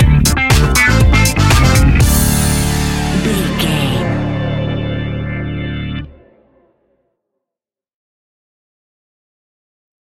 Aeolian/Minor
D
groovy
futuristic
hypnotic
uplifting
bass guitar
electric guitar
drums
synthesiser
funky house
disco house
electro funk
energetic
upbeat
synth leads
Synth Pads
synth bass
drum machines